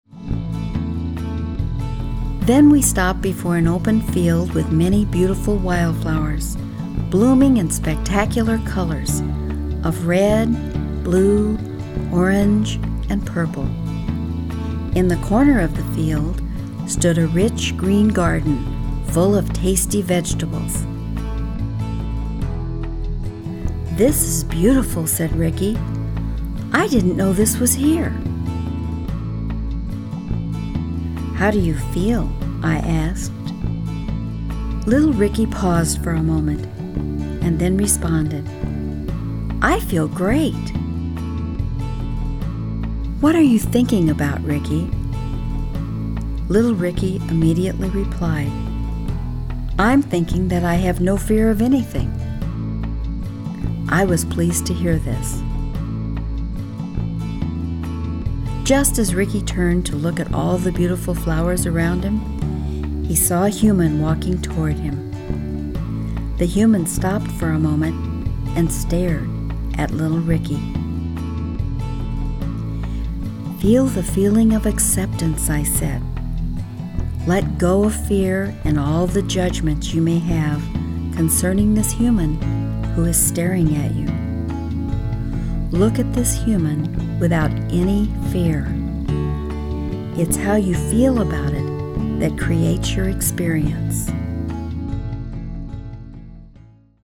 Děti si zamilují tuto fantastickou pohádku o králíčkovi Robbiem. Přírodní zvuky a hudba noci společně s Hemi-Sync®, jež doplňují tuto utěšující a konejšivou cestu do Robbieho lesního světa, zavedou Vaše dítě do hlubokého a pokojného spánku.
Verbální vedení: Anglické verbální vedení